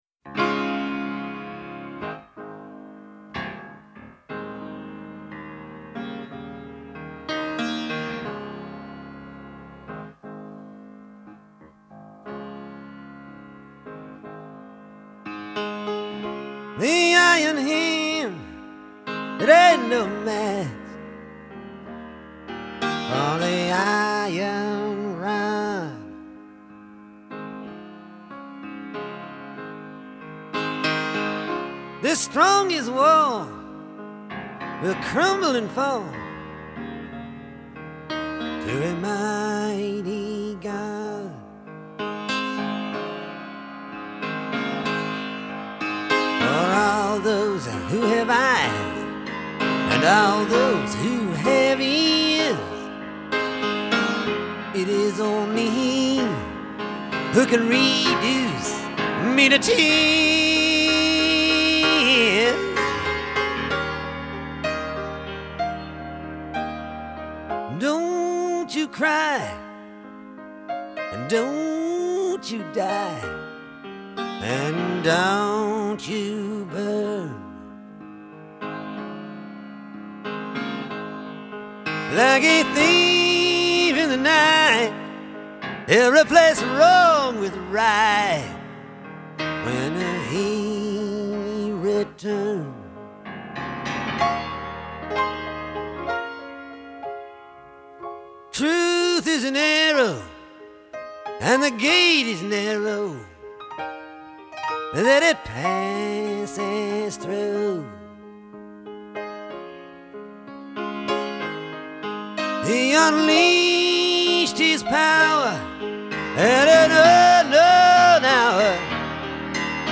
piano ballad